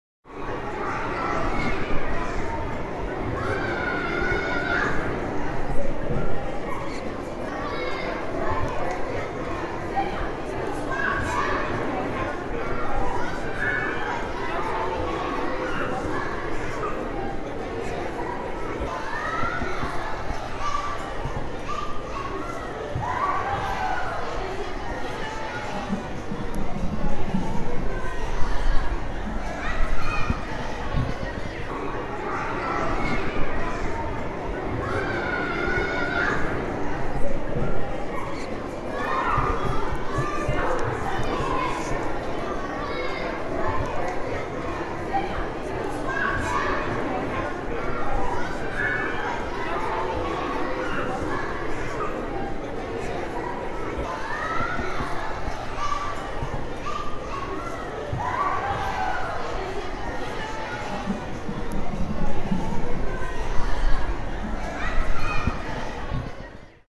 Звуки тренажерного зала
Детский гомон и возгласы в спортзале